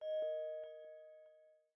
Folder_Close.ogg